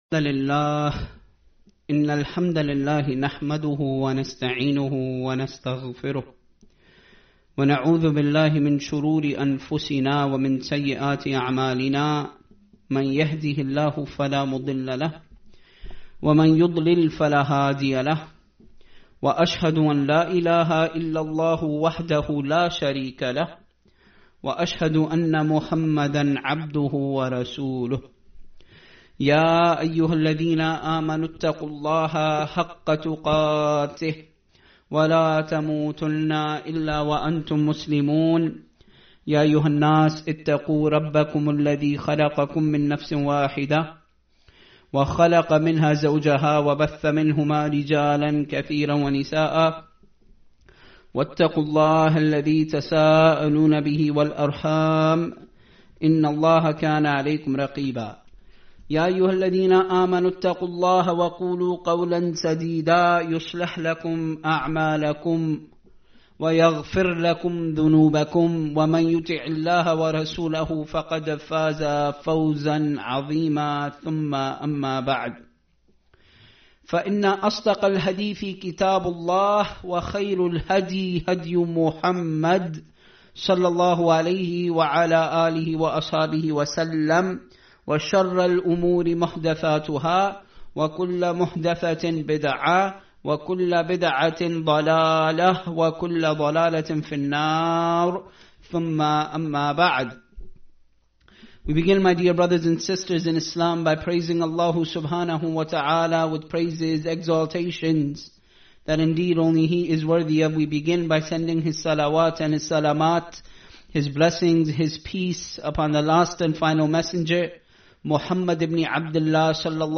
Listen to audio lectures Of ugandan muslim Sheikhs